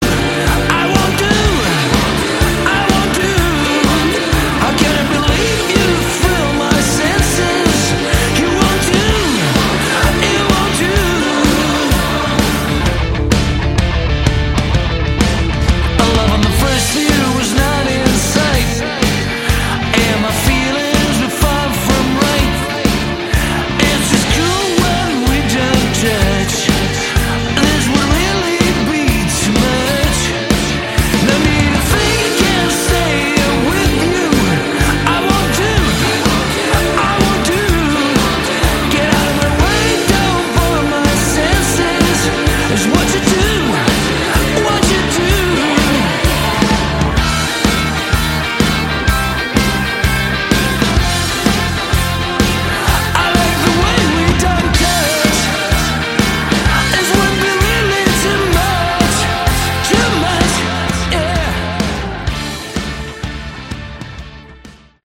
Category: AOR
lead Vocals
guitars
drums, backing vocals
bass
keyboards
backing vocals, percussion